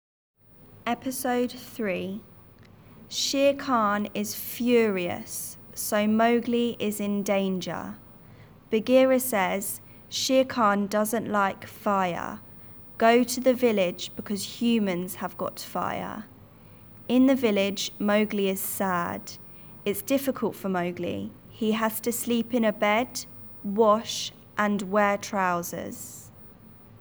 Epidode 3 lent